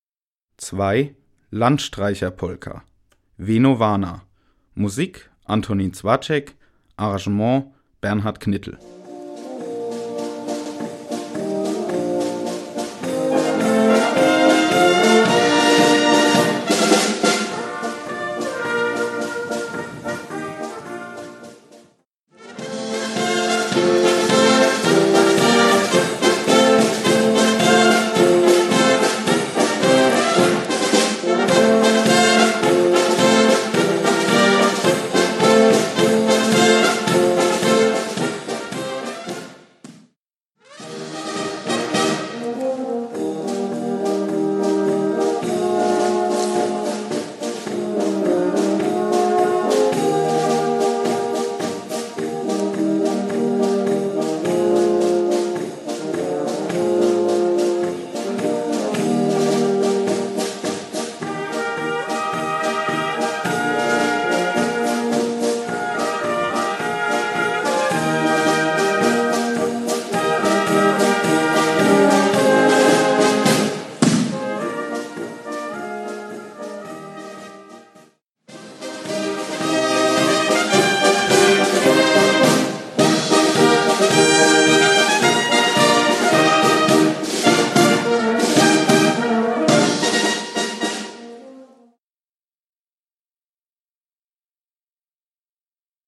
Gattung: Polka
Besetzung: Blasorchester
Diese flotte böhmisch-mährische Polka